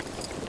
Gear Rustle Redone
tac_gear_10.ogg